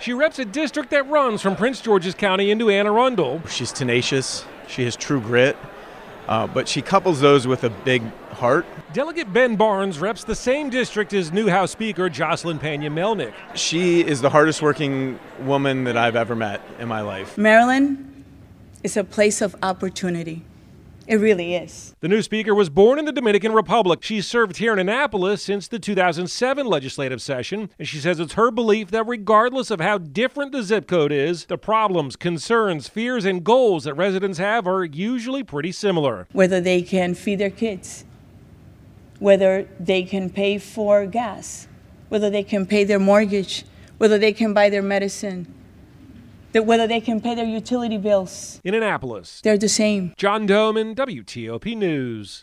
reports on Del. Joseline Pena-Melnyk becoming the new speaker of the Maryland House of Delegates